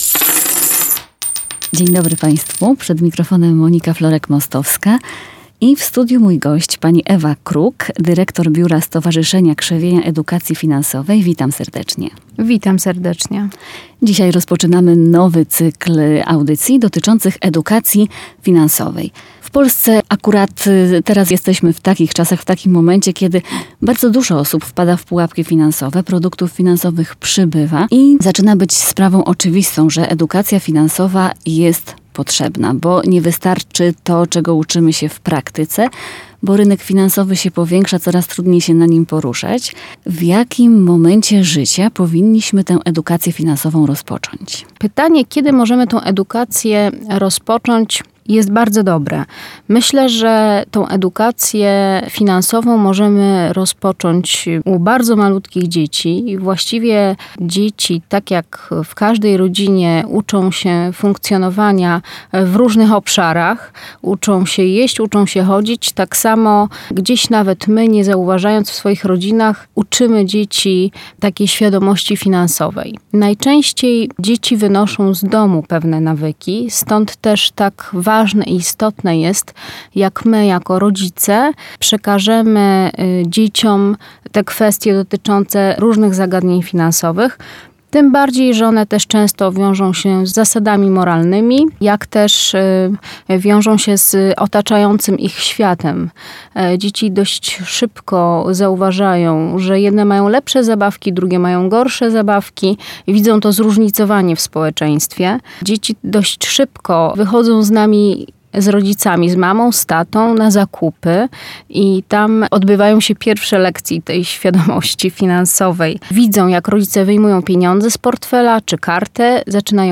Nagranie pochodzi z cyklu audycji edukacyjnych, nagranych wspólnie przez SKEF i Radio Warszawa.